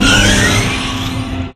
Autodoor.ogg